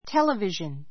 téləviʒən